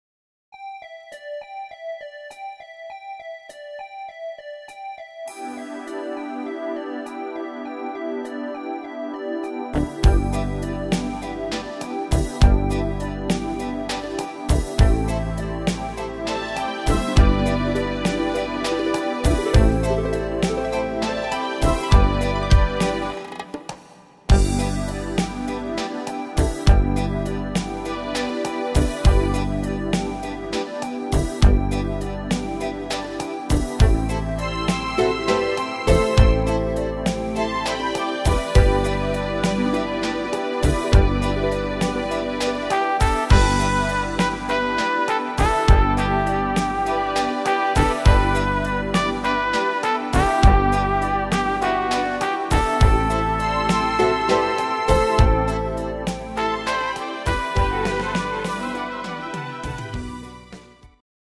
instr.Trompete